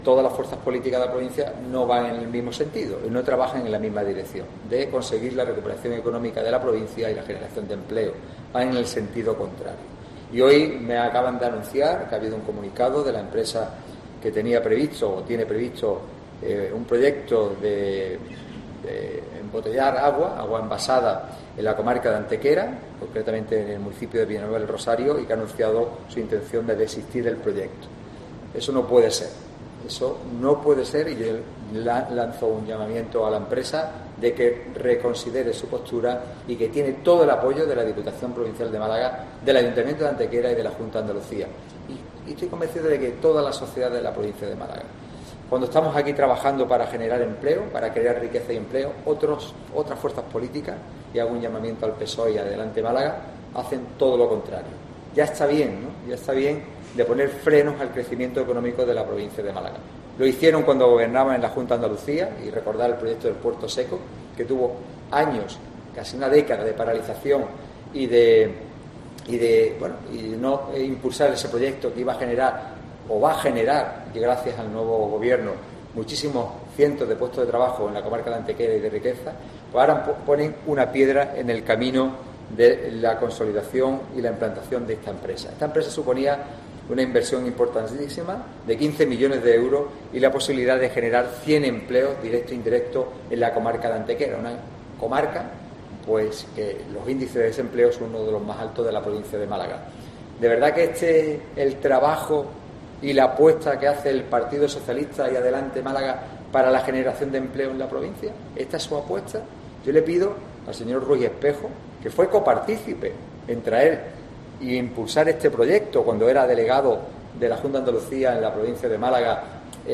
Francis Salado habla sobre el Plan Turismo Sénior para mayores.